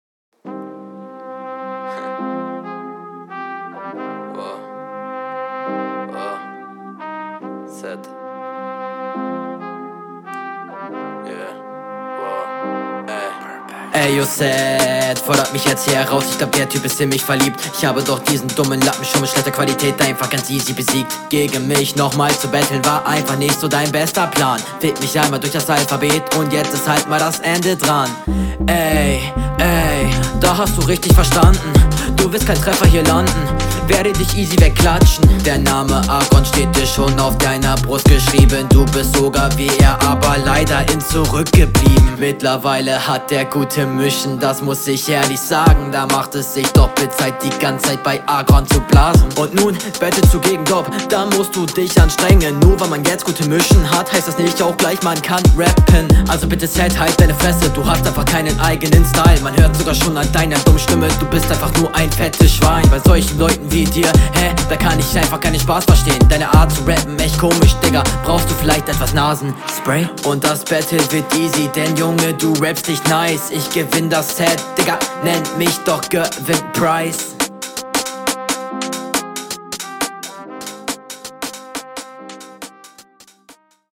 Flow: Super Solide, nur die Staccato flows wirken irgendwie nicht sonderlich gekonnt Text: Kann man …
Flow: Flow find ich sehr solide.